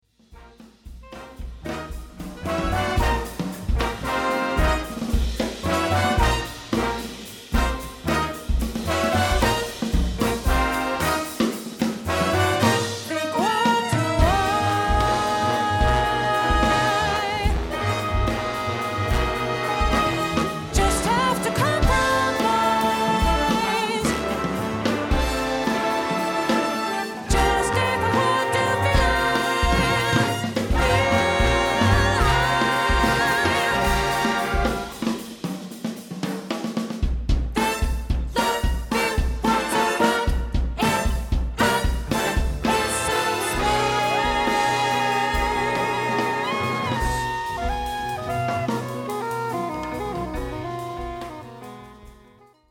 eine Suite in 3 Sätzen